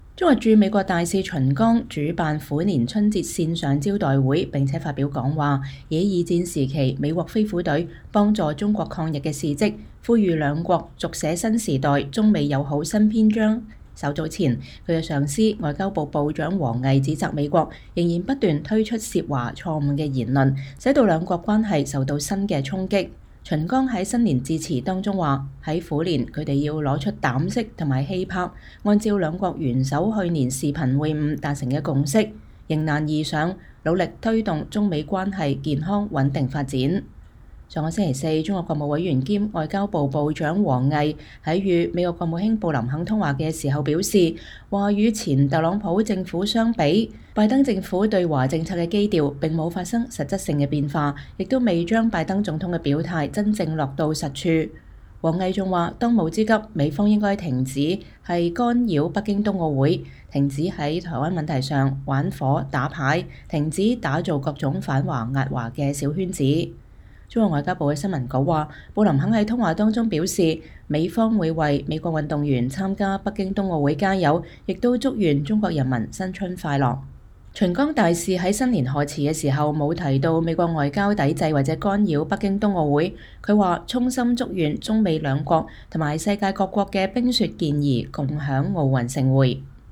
中國駐美國大使秦剛主辦虎年春節線上招待會並發表講話，以二戰時期美國“飛虎隊”幫助中國抗日的事蹟呼籲兩國“續寫新時代中美友好新篇章”。